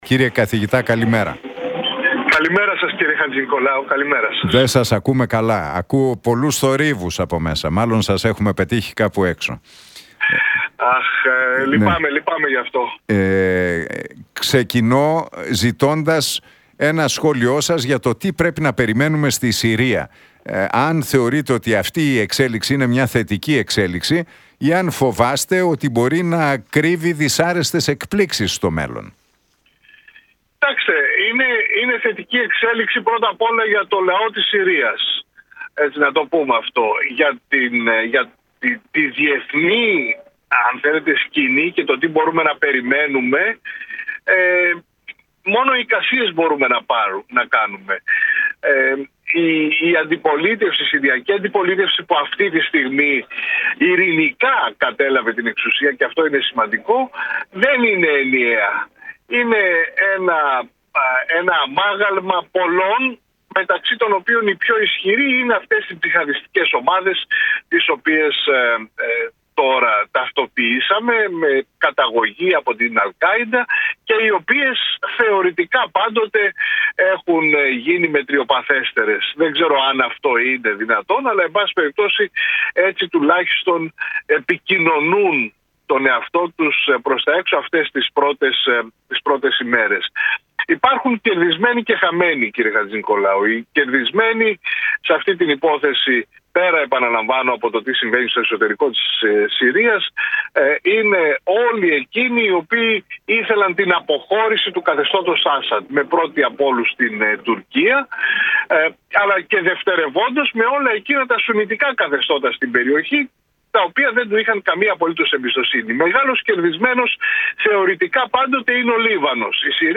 «Οι χαμένοι είναι το Ιράν, δεν υπάρχει καμία αμφιβολία, είναι η Χεζμπολάχ και σε κάποιο βαθμό, αλλά αυτό μένει να φανεί, είναι όλοι εκείνοι οι οποίοι επένδυαν στο καθεστώς Άσαντ για την προβολή ισχύος στην περιοχή, όπως είναι η Ρωσία», σημείωσε ο καθηγητής Διεθνών Σχέσεων.